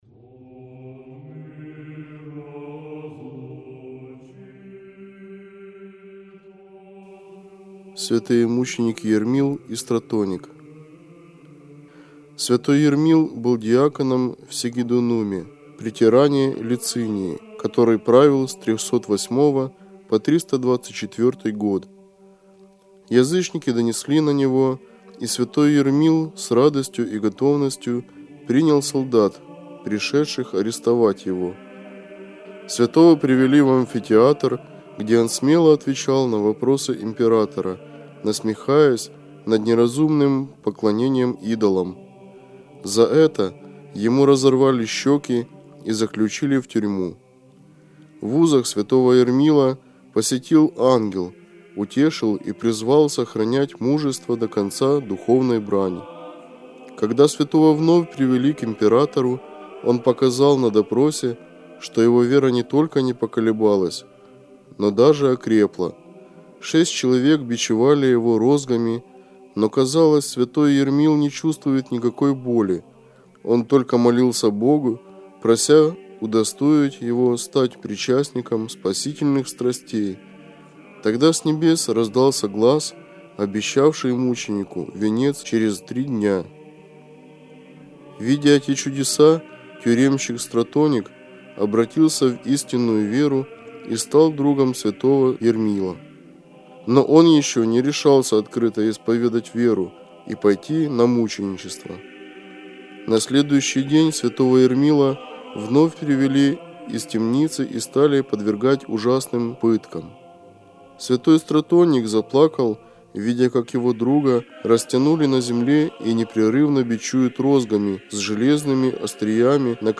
Читает иеромонах